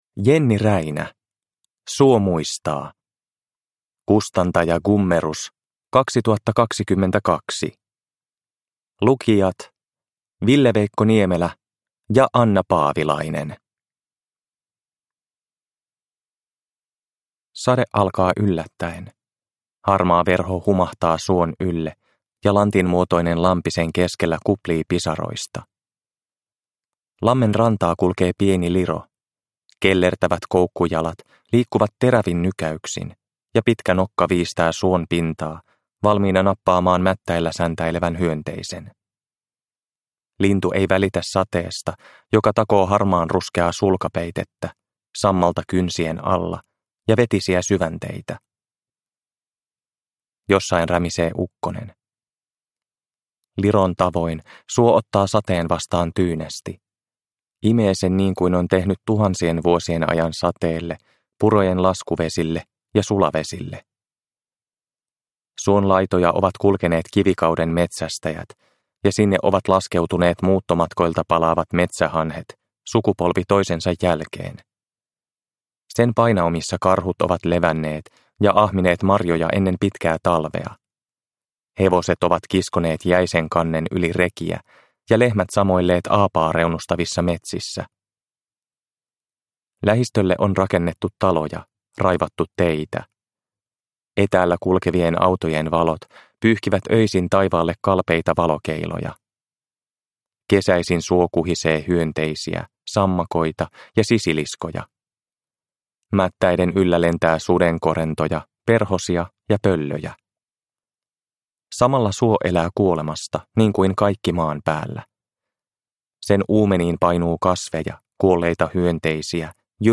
Suo muistaa – Ljudbok – Laddas ner